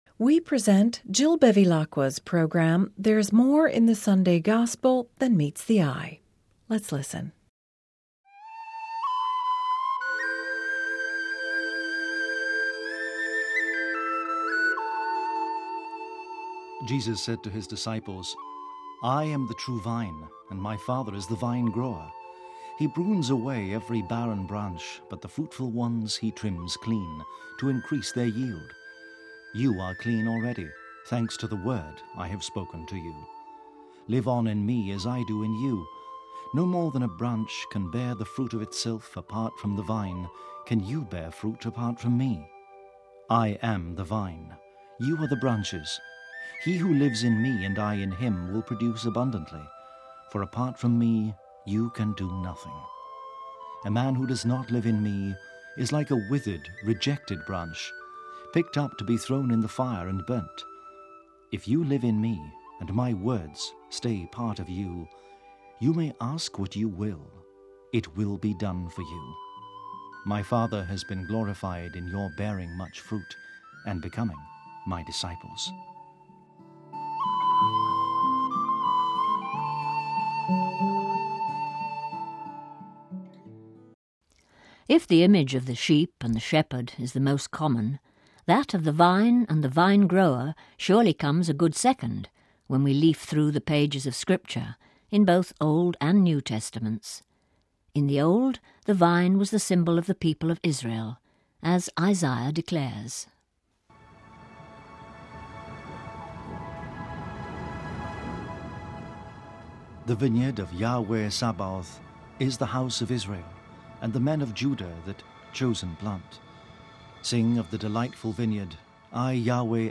readings and reflections for the Fifth Sunday of Easter